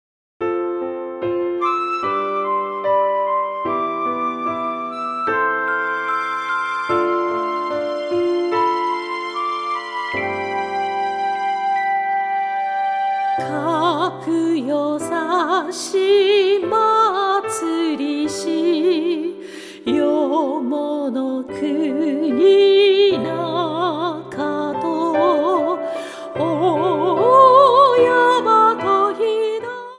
８つの曲を通して、澄んだ歌声と優しい響きが、心に静けさと力を届けます。